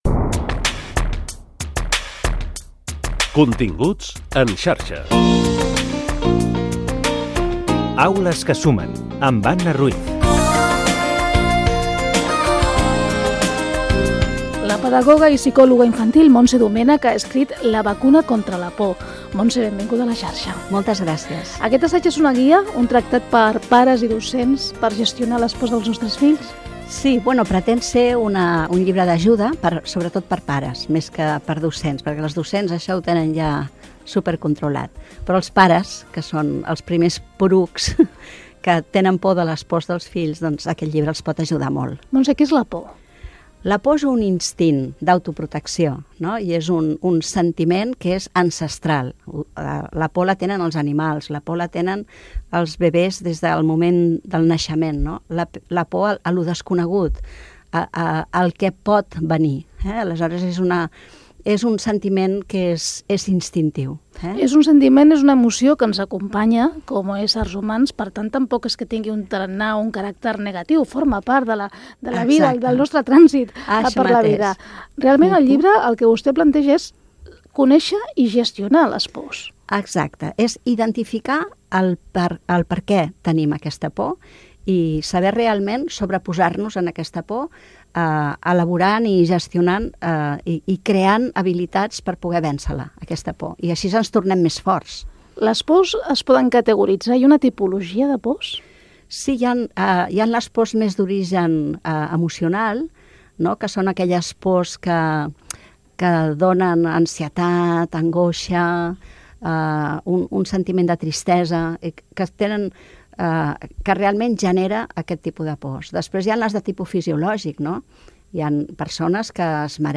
Fragment d'una entrevista